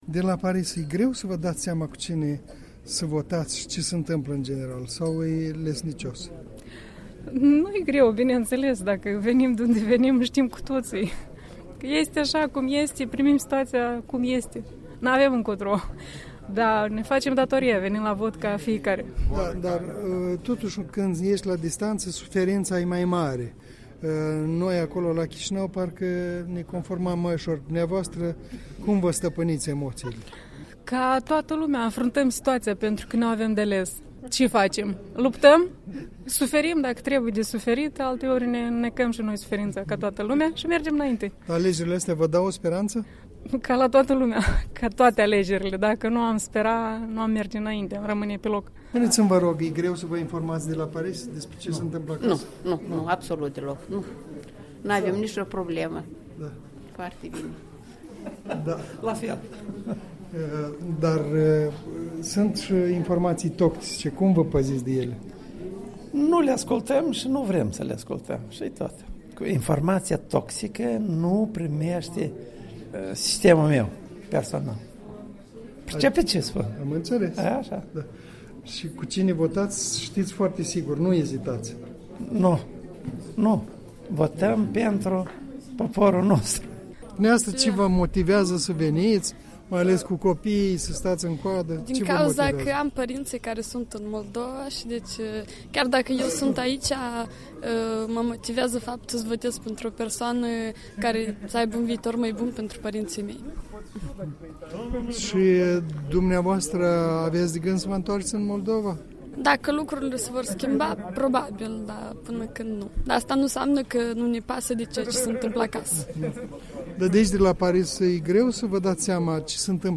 Vox Pop Paris